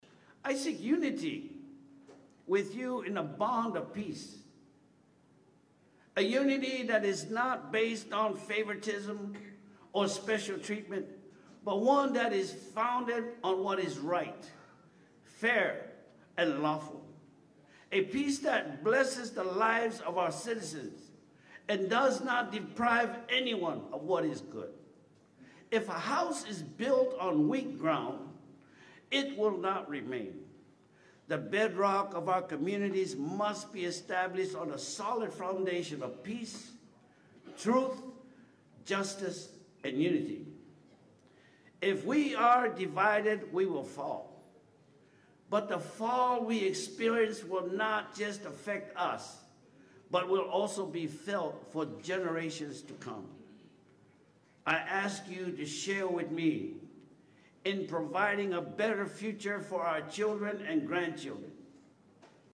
Swains Representative Su’a Alex Jennings in his remarks asked that divisions and disagreements be left in the past, while leaders work in unity and serve the people rather than be served.